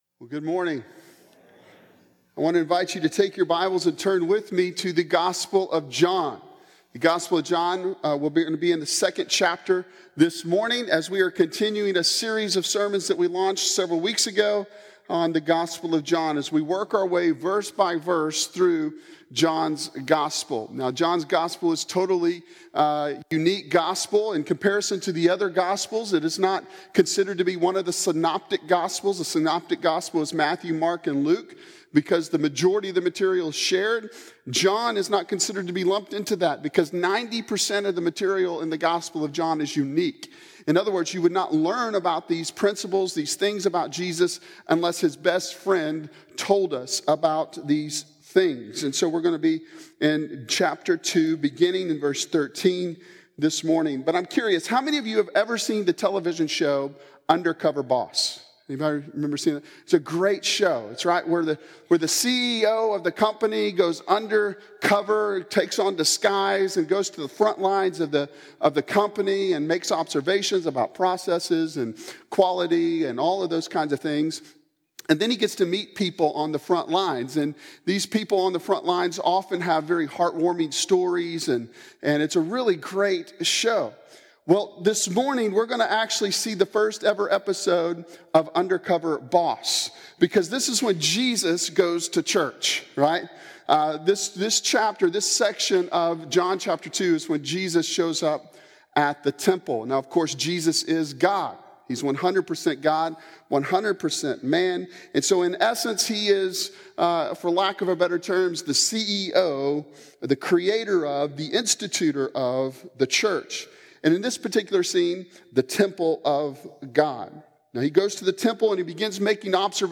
The Gospel of John Sermon Series Passage: John 2:13-25